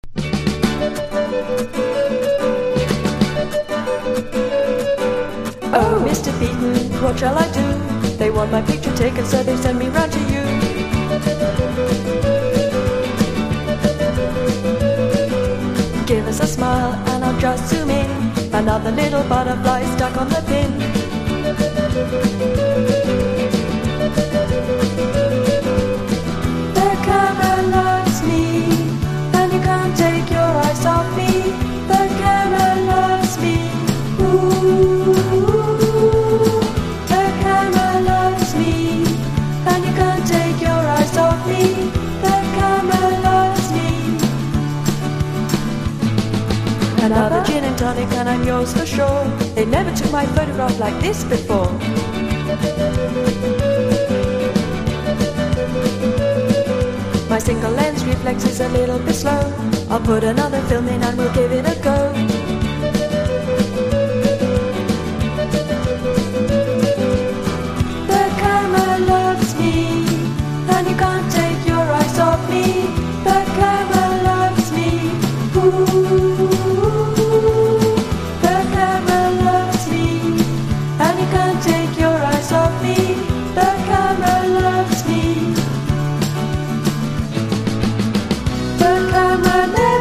1. NEW WAVE >
# NEO ACOUSTIC / GUITAR POP
ネオアコ名盤！